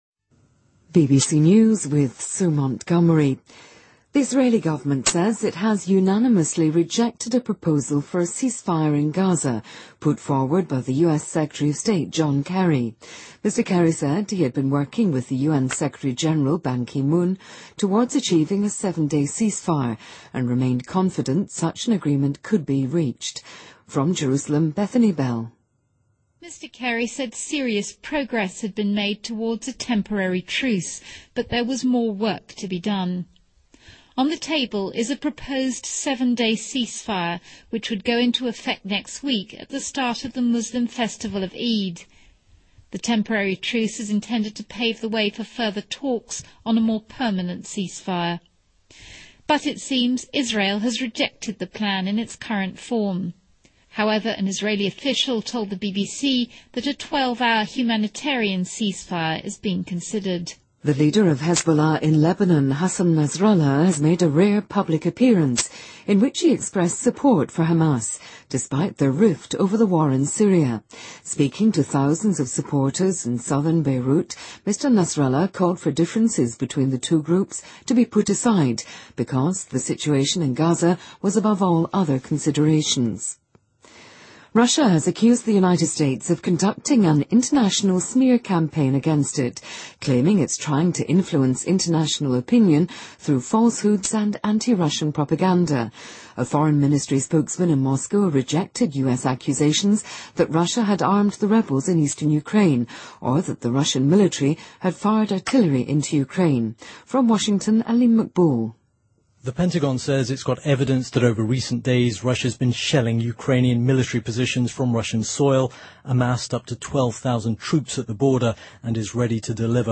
BBC news,维基百科宣布禁止美国众议院的电脑对页面进行编辑